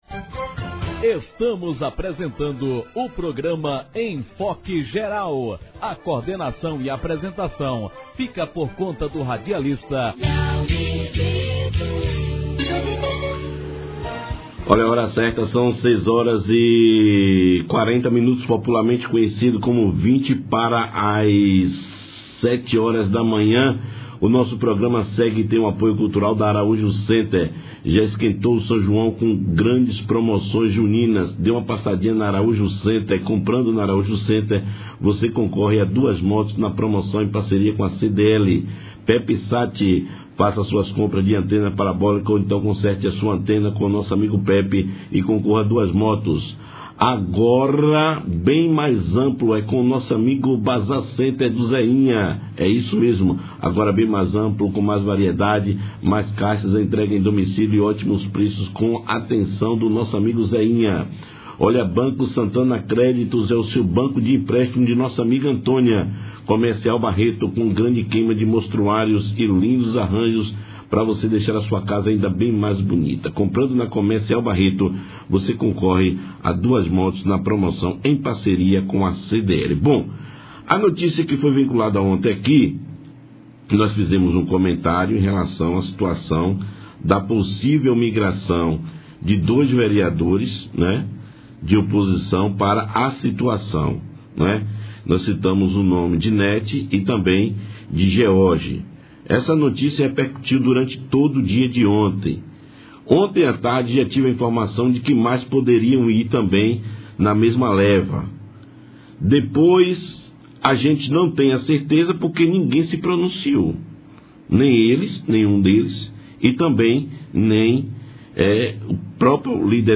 Na manhã desta sexta-feira (17), durante o Programa Enfoc Geral, foi realizada uma enquete com duração de 10 minutos para uma avaliação popular sobre a possível migração dos vereadores George Leão e Nete Argolo da oposição migrando para composição de chapa com o atual prefeito Emiran, 10 responderam SIM achando que há essa possibilidade e 08 responderam NÃO .